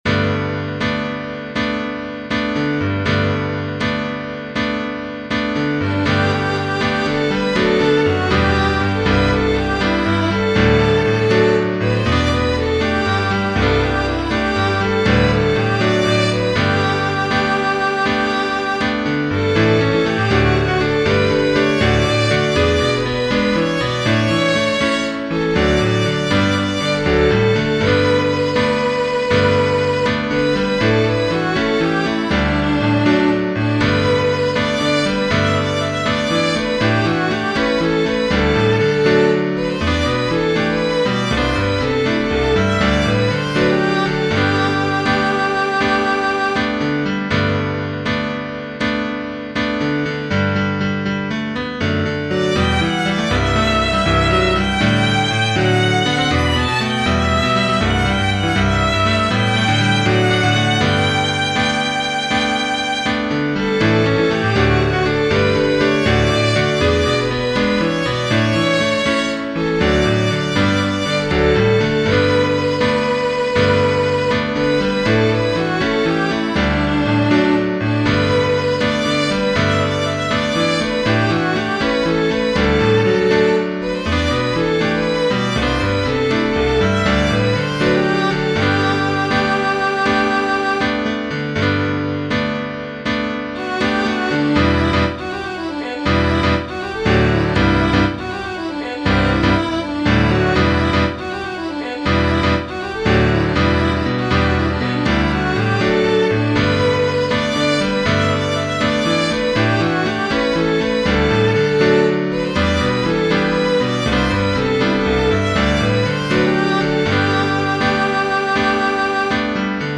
Voicing/Instrumentation: Violin Solo We also have other 6 arrangements of " Have I Done Any Good ".